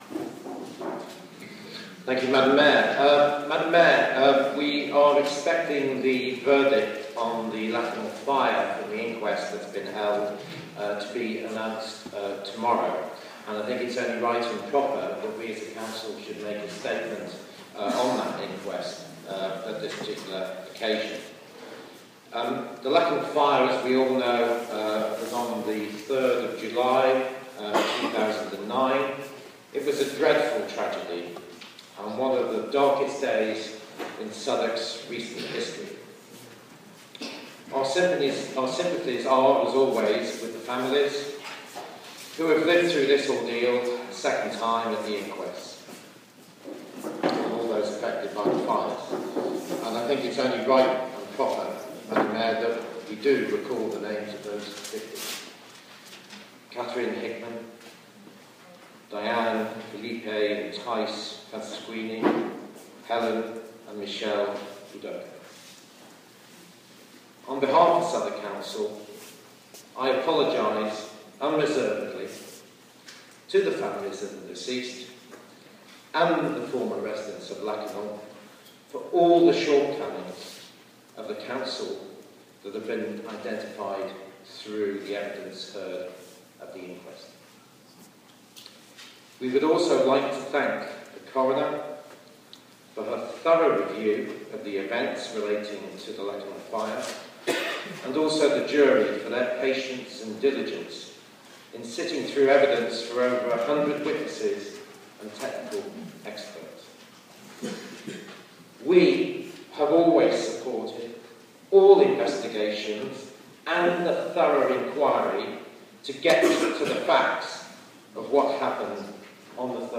Cllr Ian Wingfield speaks at Council Assembly March 2013